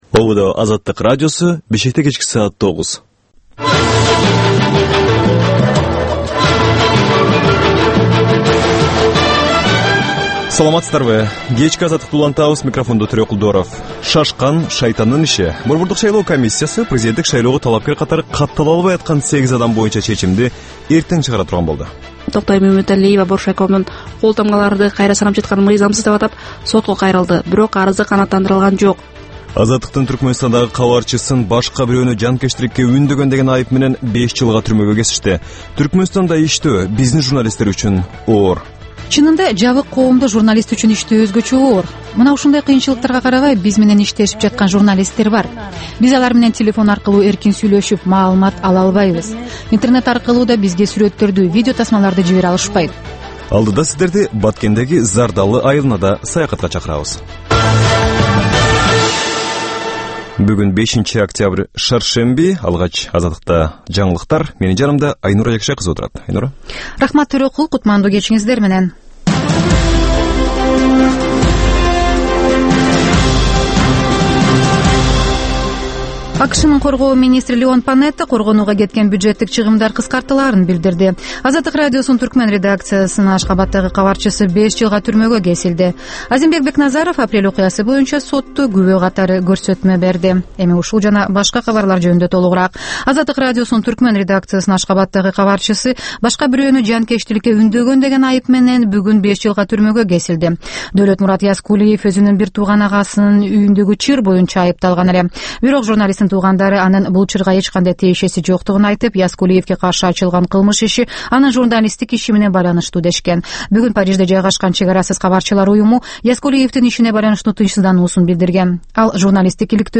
Азаттыктын кабарлары